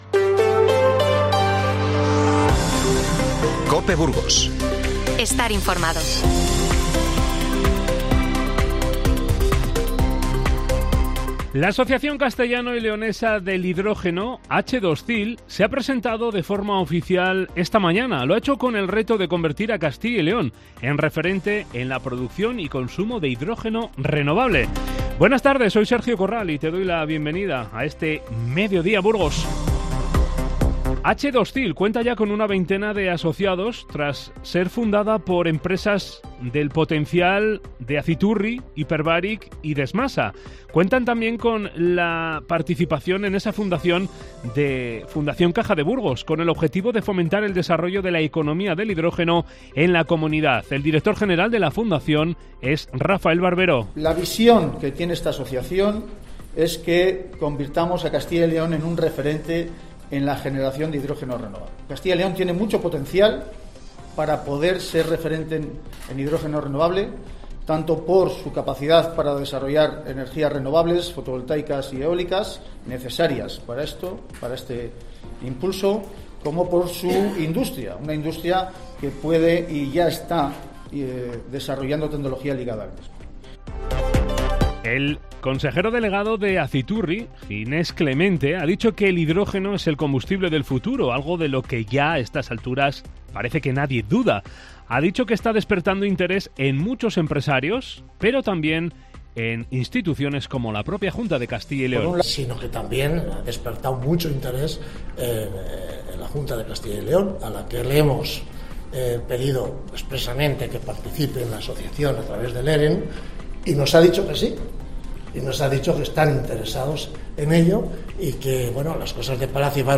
INFORMATIVO Mediodía 16-03-23